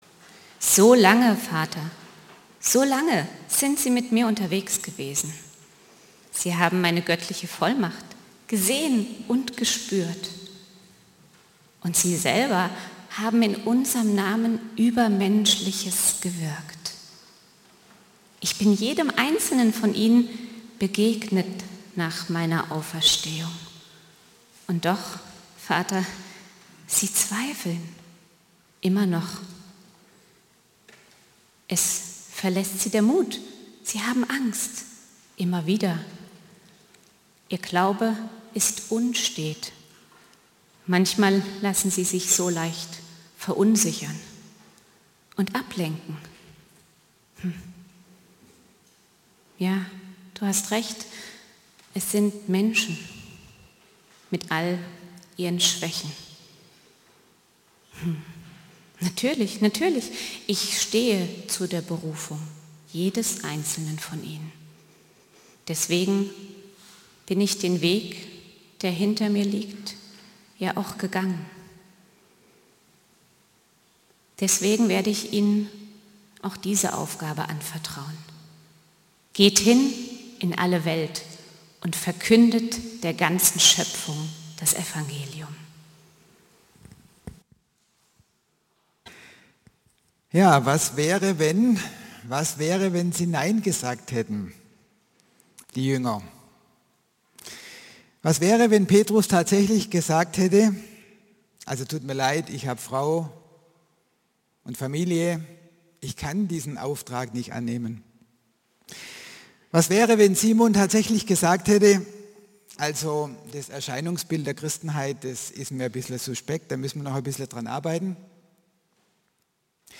Predigten aus einANDERERGottesdienst